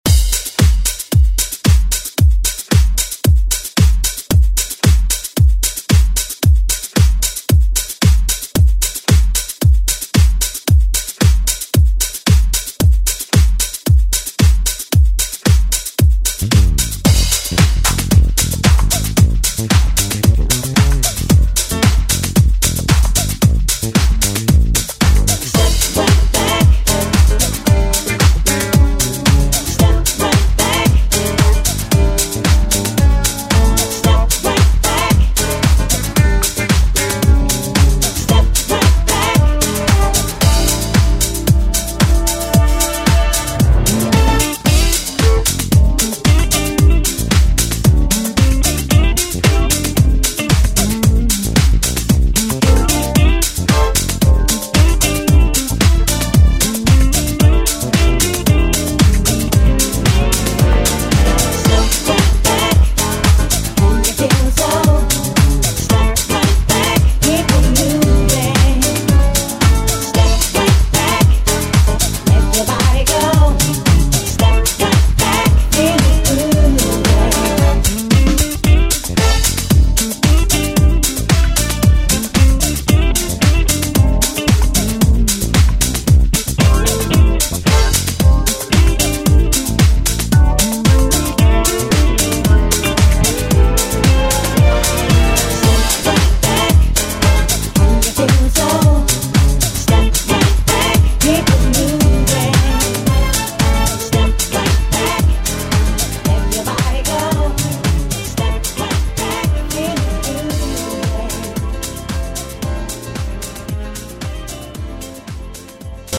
Genres: 90's , RE-DRUM , ROCK
Clean BPM: 145 Time